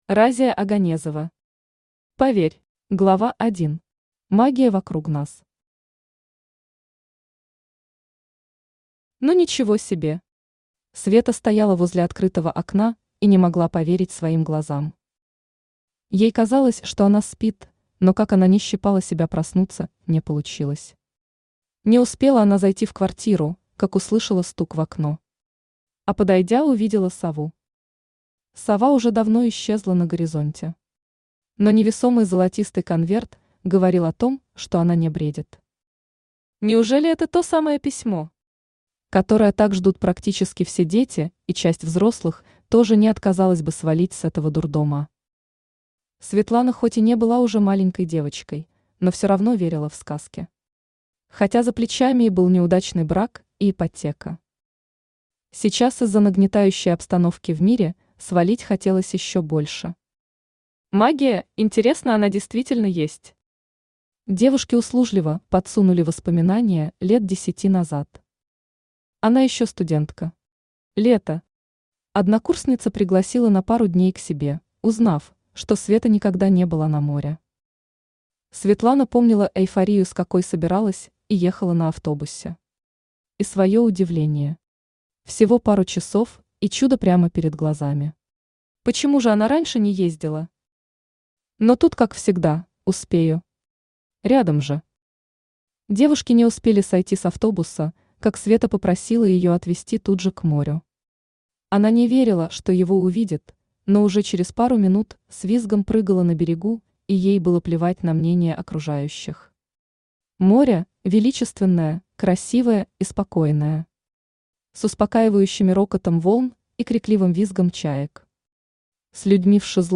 Аудиокнига Поверь | Библиотека аудиокниг
Aудиокнига Поверь Автор Разия Оганезова Читает аудиокнигу Авточтец ЛитРес.